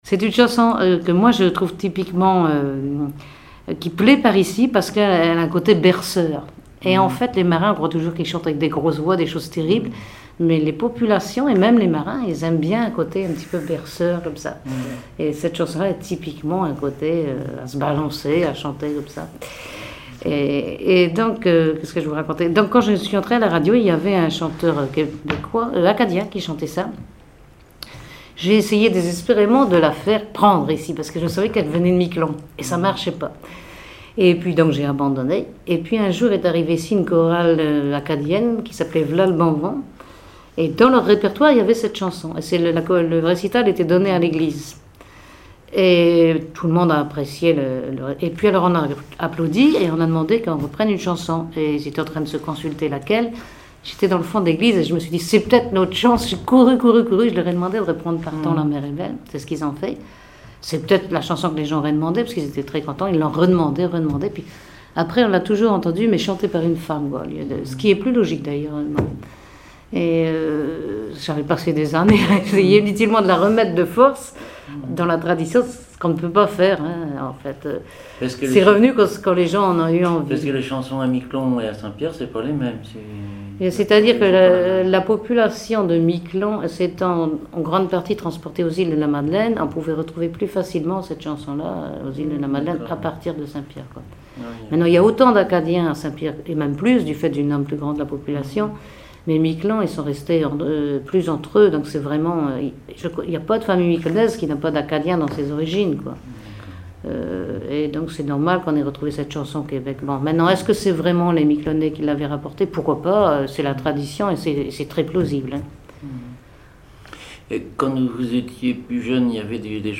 Conversation sur les musiciens de Saint-Pierre et Miquelon
Catégorie Témoignage